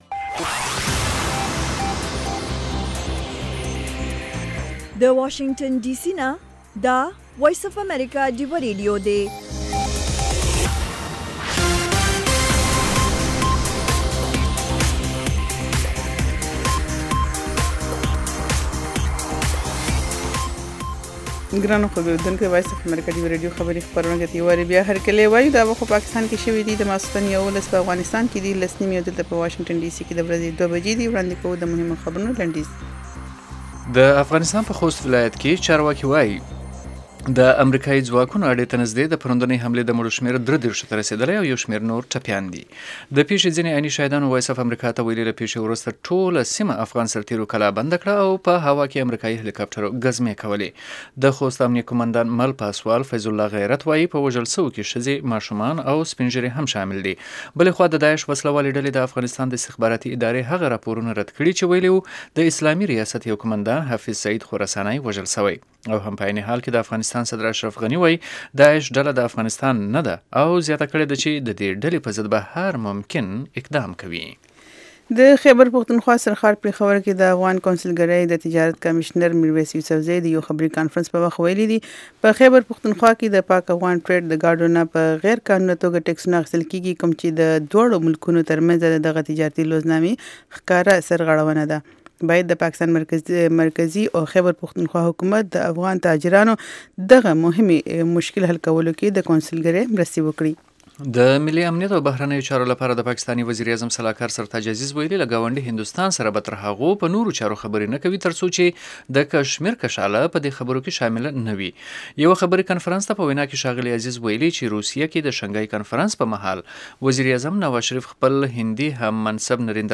په دې خپرونه کې اوریدونکي کولی شي خپل شعر یا کوم پیغام نورو سره شریک کړي. د شپې ناوخته دا پروگرام د سټرو اوریدنکو لپاره ښائسته خبرې او سندرې هم لري.